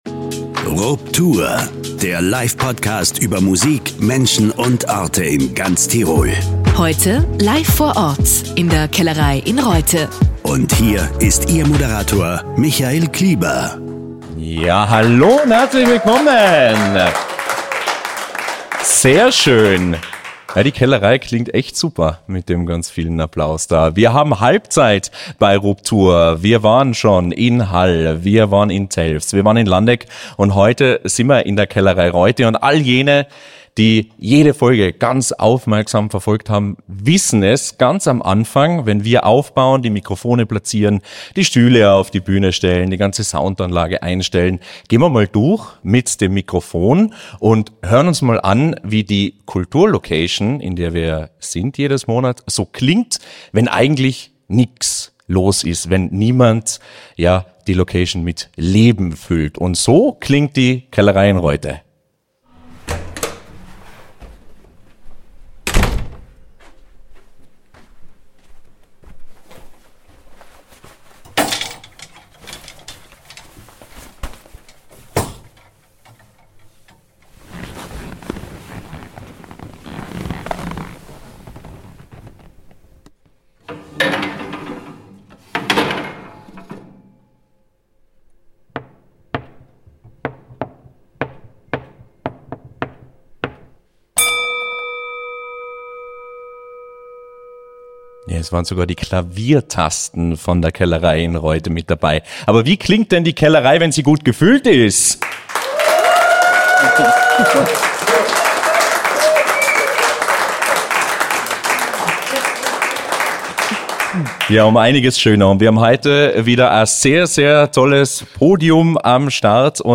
Tirols Livepodcast über Musik, Menschen und Orte zu Gast in der Kellerei Reutte.